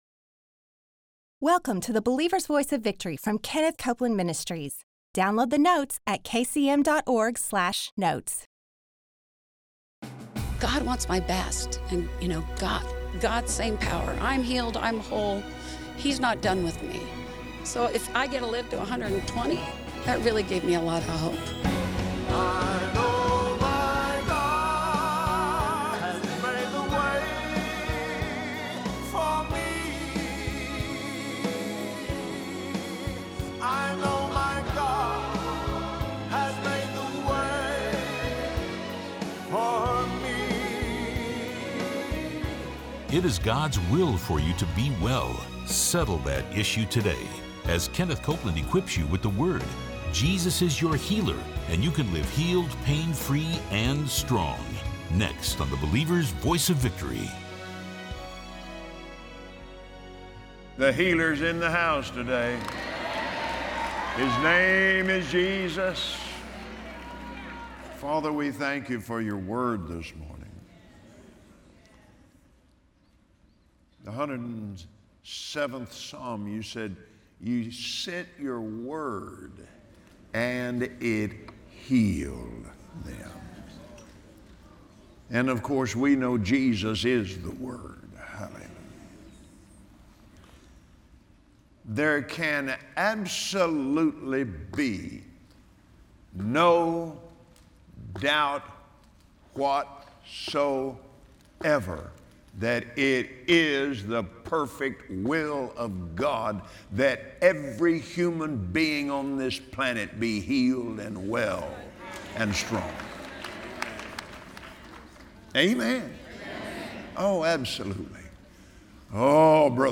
Believers Voice of Victory Audio Broadcast for Tuesday 05/02/2017 Jesus paid the price for you to live healed! Watch Kenneth Copeland on Believer’s Voice of Victory explain how it’s the will of God for every person to live free from sin, sickness, disease and poverty.